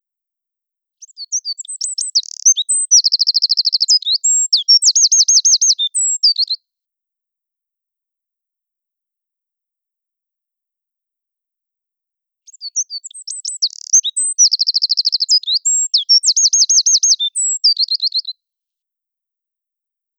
winterkoning-2.wav